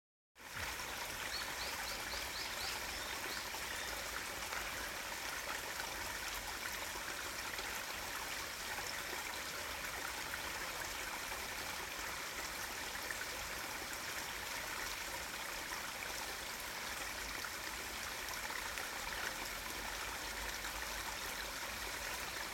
무더운 여름날, 소리만으로 시원해지는 ASMR 퀴즈
깊은 숲을 따라 이어지는 오솔길을 따라 이 소리에 정취를 느껴보세요.
수컷이 높은 소리를 내어 암컷을 찾아요.